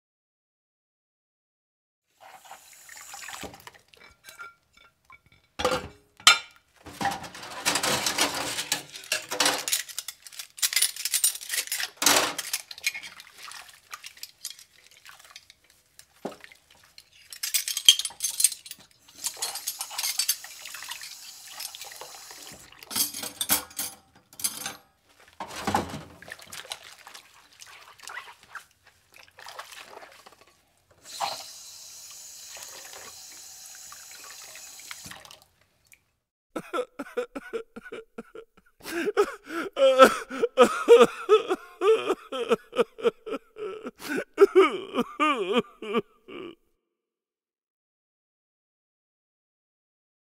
دانلود آهنگ ظرف شستن 2 از افکت صوتی طبیعت و محیط
دانلود صدای ظرف شستن 2 از ساعد نیوز با لینک مستقیم و کیفیت بالا
جلوه های صوتی